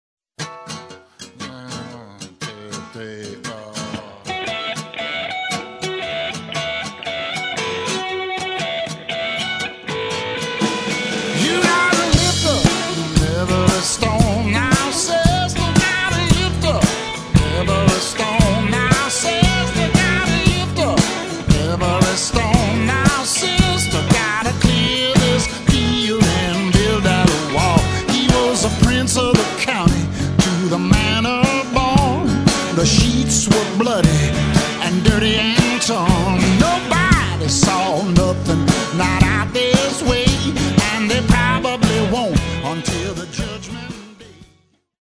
Drum Remix